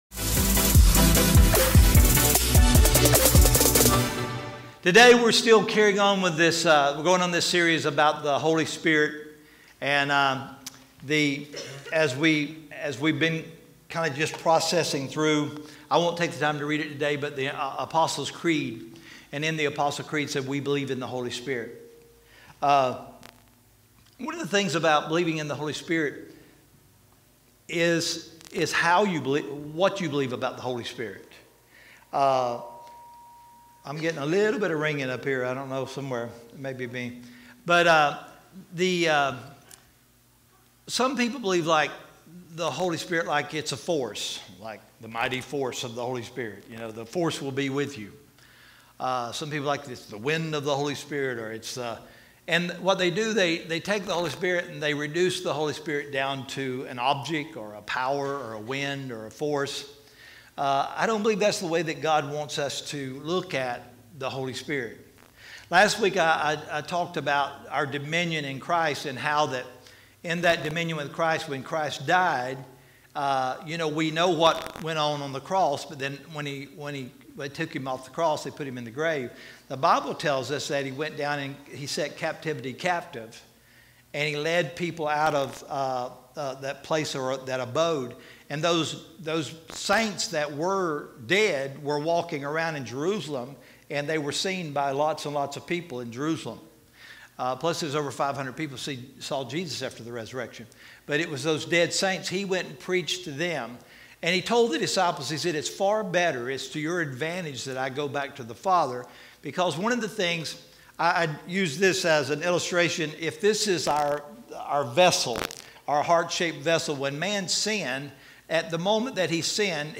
The next part of our sermon series “Holy Spirit”